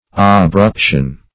Abruption \Ab*rup"tion\, n.